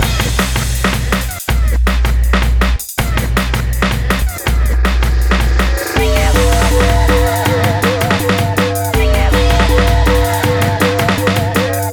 32 Hardcore-d.wav